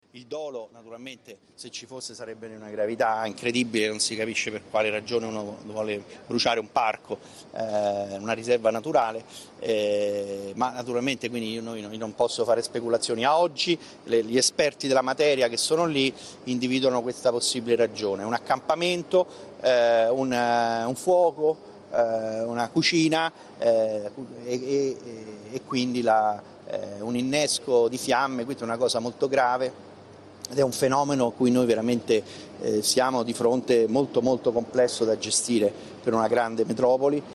Sul posto poco si è recato il sindaco Gualtieri e quando gli è stato chiesto se si possa ipotizzare il dolo ha risposto così: